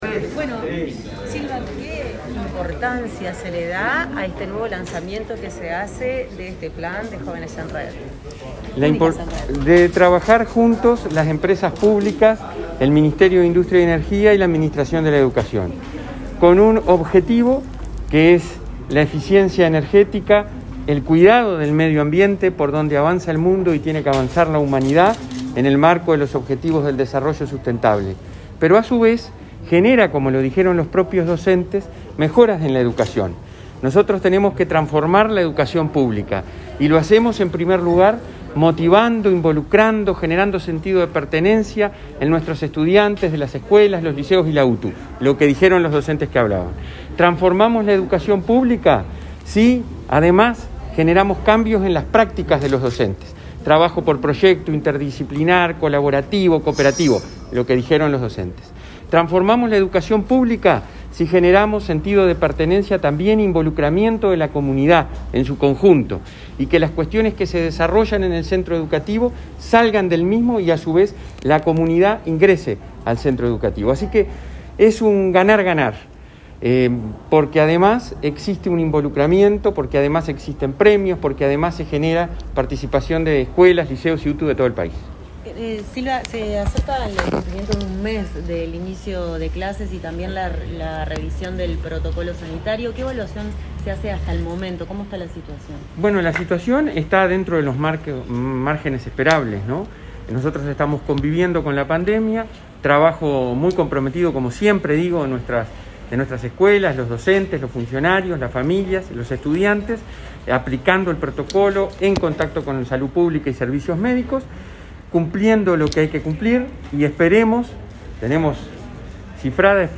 El presidente de la ANEP, Robert Silva, participó este martes 29 del lanzamiento del Concurso de Eficiencia Energética y, luego, dialogó con la prensa